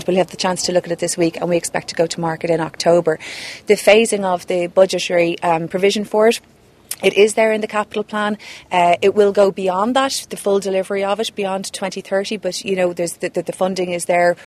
Minister Jennifer Carroll MacNeil says its funded………….